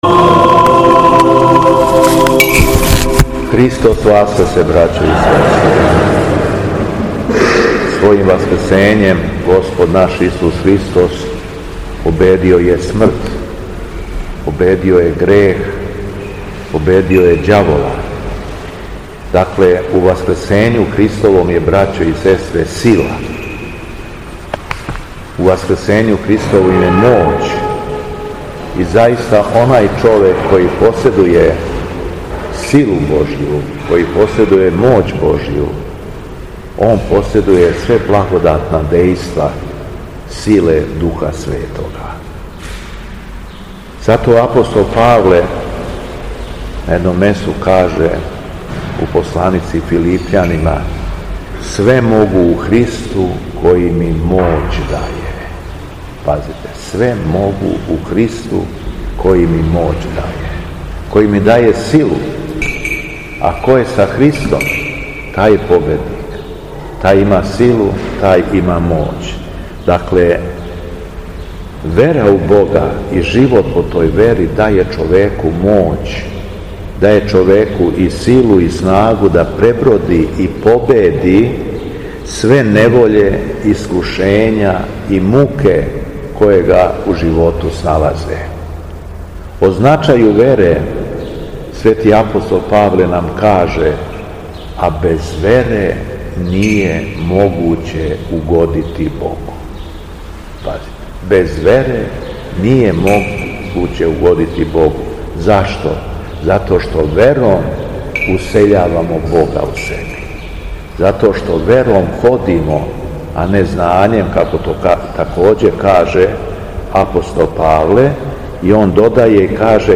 Његово Високопреосвештенство Митрополит шумадијски господин Јован, служио је свету архијерејску литургију у понедељак шести по Пасхи, двадесет и шестог маја, у храму Светога Саве у крагујевачком насељу Аеродром.
Беседа Његовог Високопреосвештенства Митрополита шумадијског г. Јована
По прочитаном Јеванђељу Митрополит Јован се обратио верном народу надахнутом беседом: